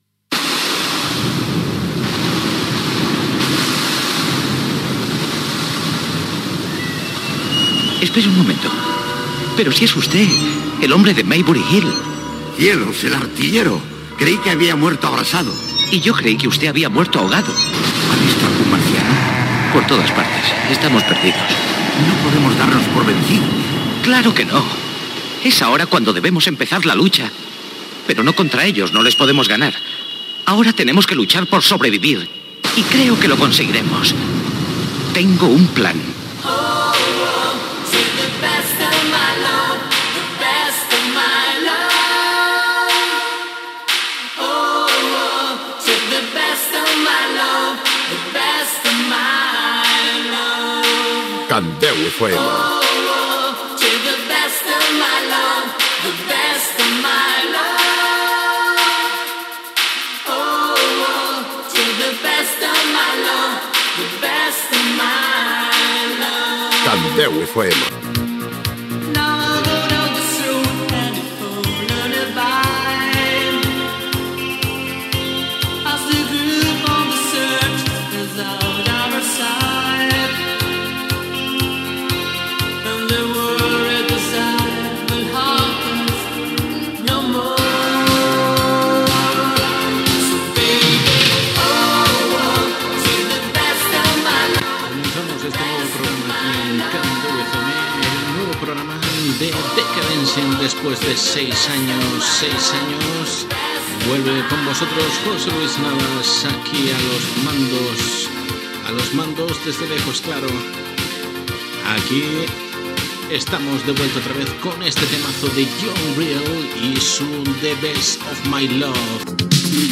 Indicatiu de la ràdio, temes musicals i presentacions
Musical